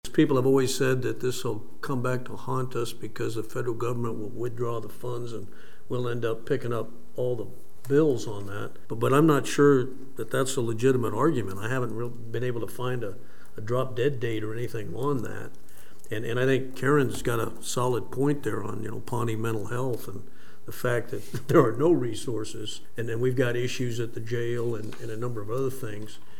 Mayor Wynn Butler says there is one potential drawback he could foresee.